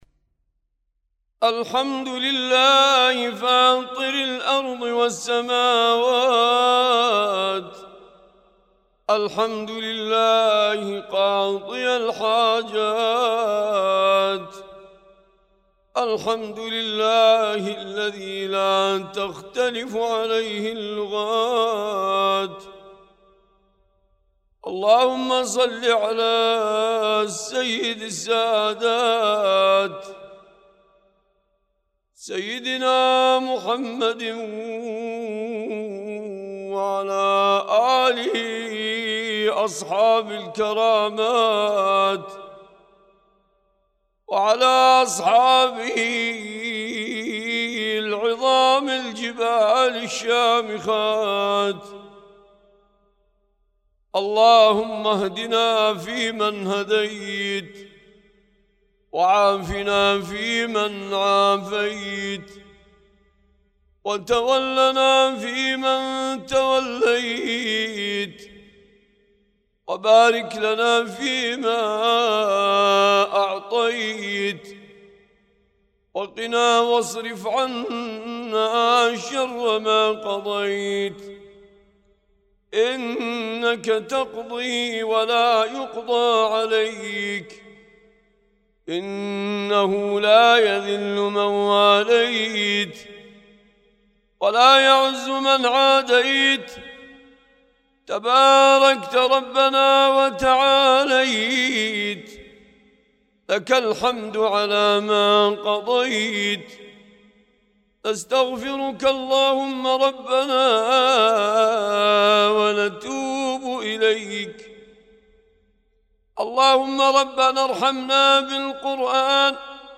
دعاء القنوت